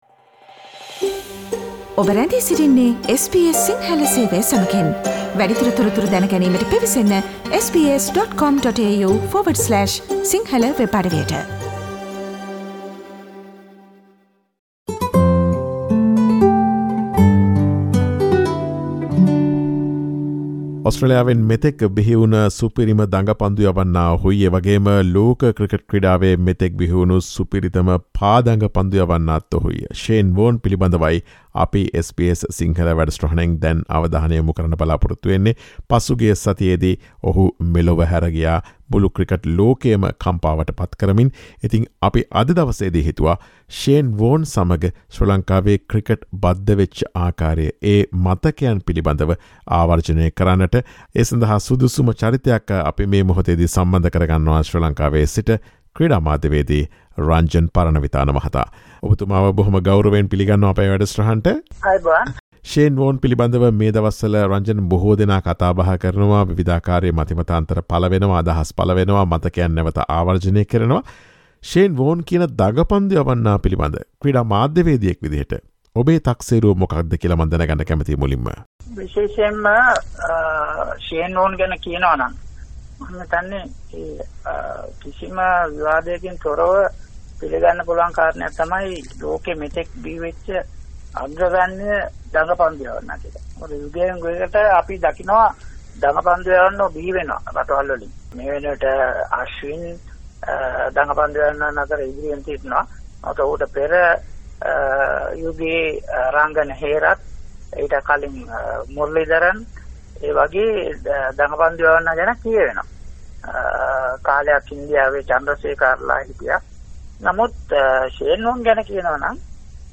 Listen to the SBS Sinhala radio interview that evokes the memory of the late Shane Warne as well as his dealings with Sri Lankan cricket.